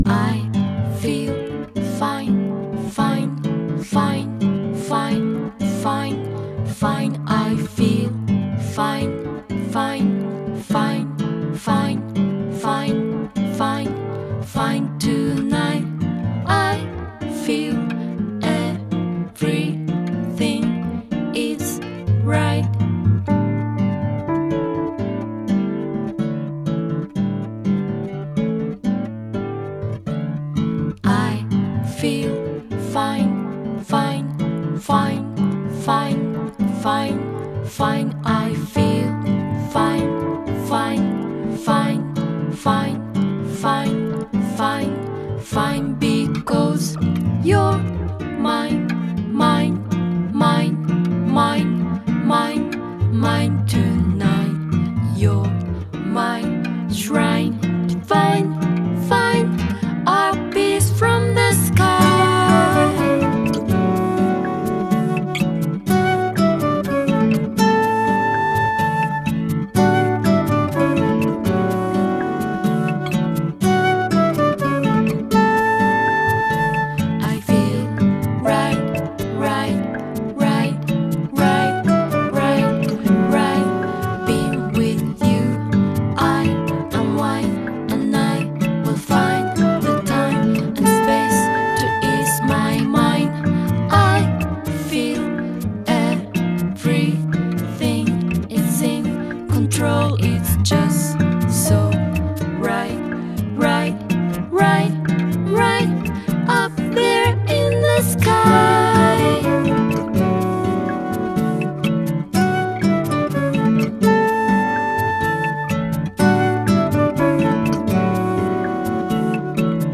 -the cut&paste electronic techniques
-the interaction between electronics and guitars
-the whimsical-ish vocals